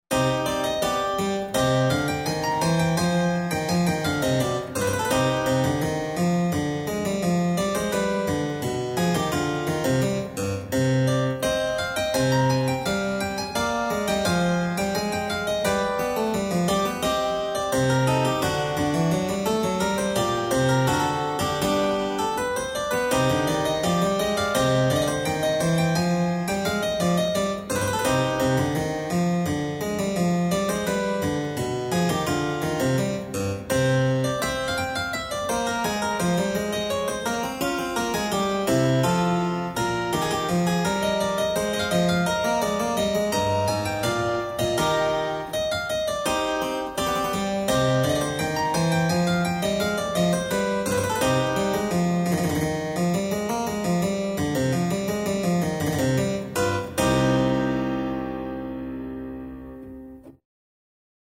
Adagio [70-80] plaisir - clavecin - concerto - menuet - cour